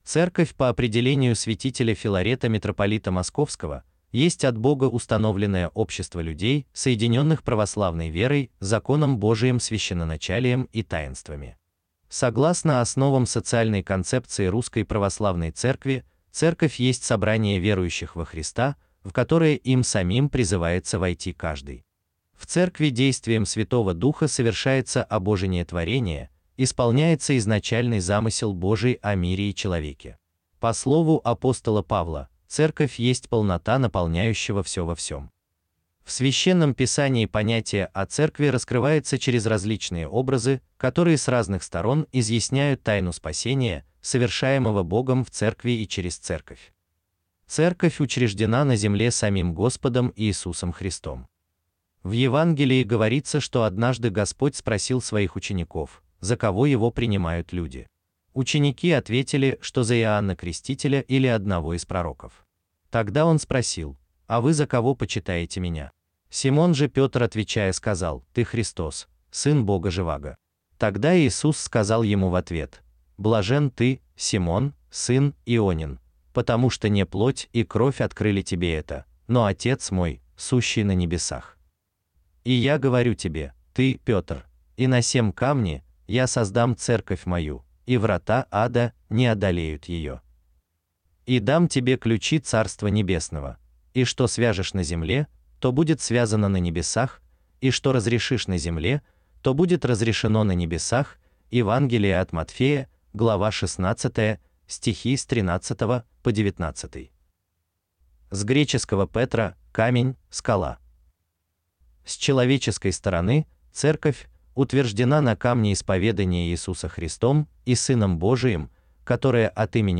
Аудиокнига Основы канонического устройства и литургической жизни Православной Церкви | Библиотека аудиокниг
Aудиокнига Основы канонического устройства и литургической жизни Православной Церкви Автор Коллектив авторов Читает аудиокнигу Искусственный интеллект Ivan.